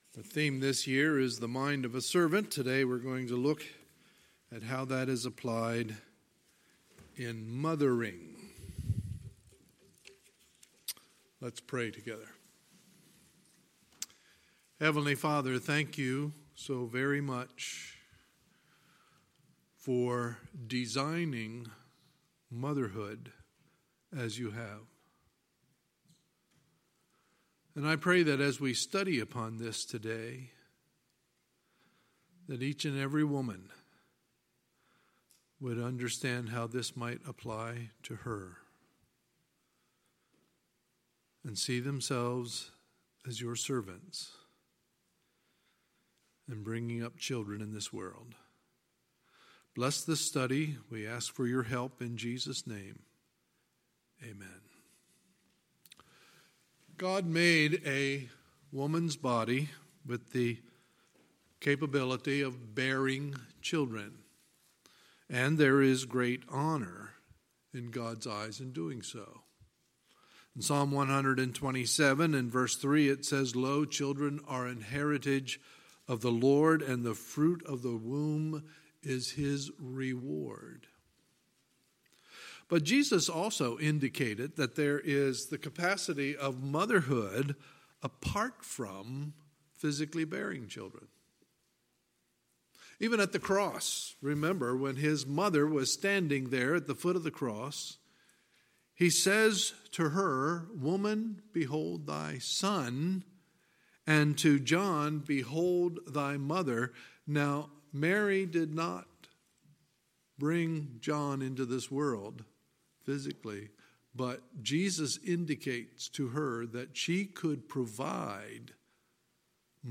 Sunday, May 12, 2019 – Sunday Morning Service